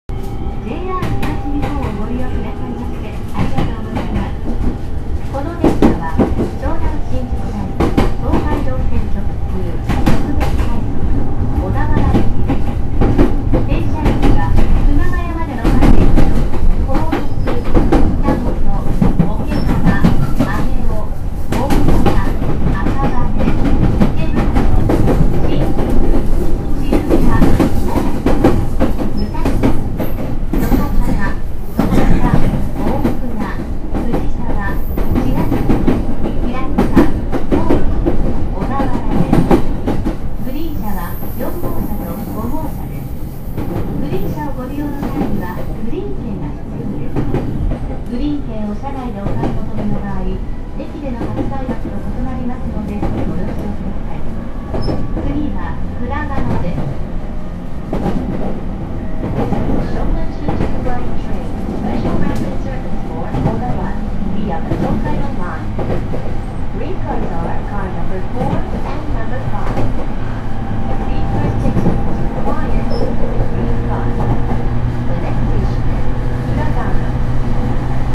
高崎駅発車後
停車駅案内、グリーン車の案内、マナー放送が日本語と英語でフルに流れるため非常に長い放送となっている。
syounanshinjuku_takasaki2.wma